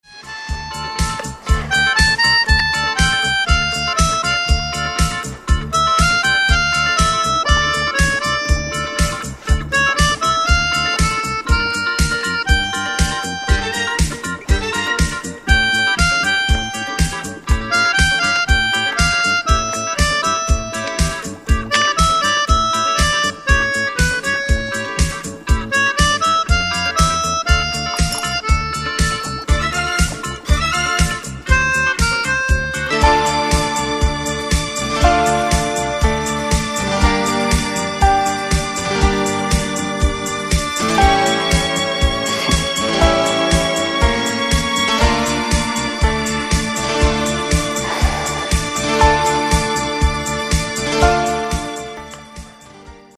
Весьма приличное звучание!